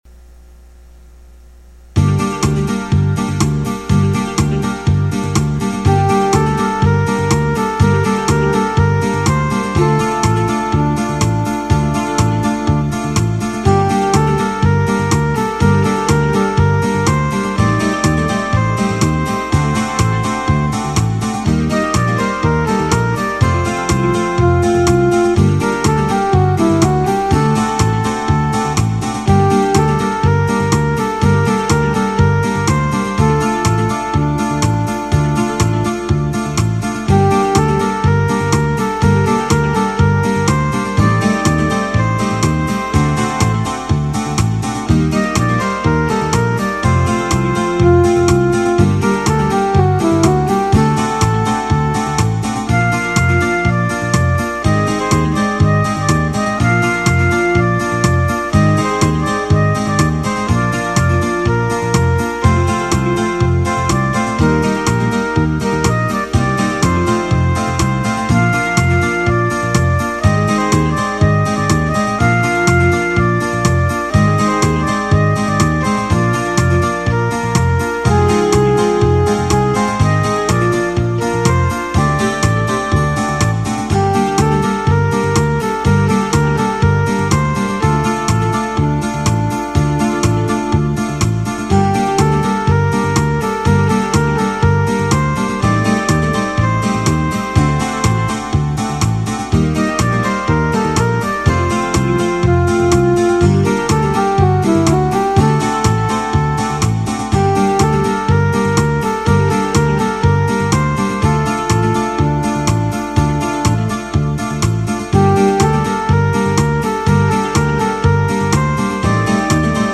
Le TRK est le fichier midi en format mp3 sans la mélodie.